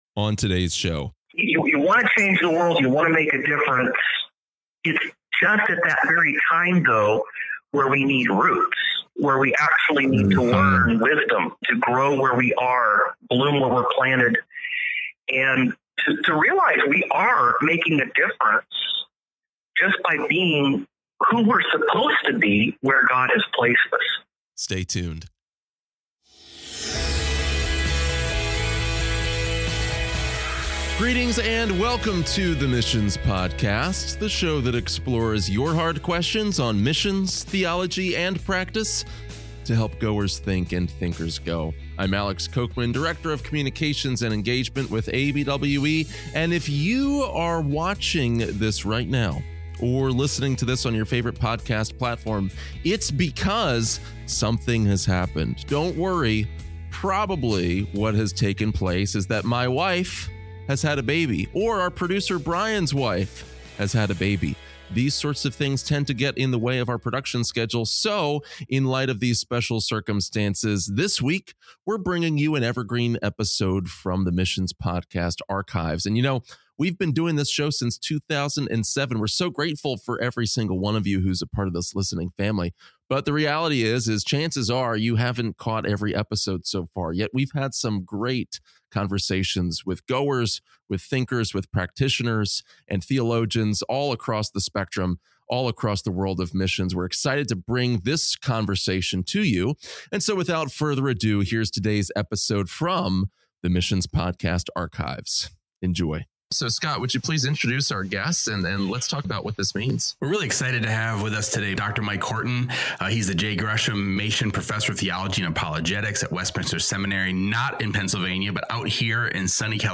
we dig into the archives for a conversation